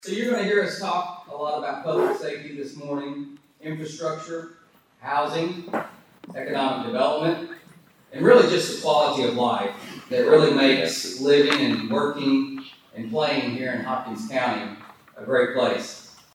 State of the Cities and County Address Held Thursday
Local officials and community members gathered this morning for an update on key issues and developments affecting Hopkins County and its cities.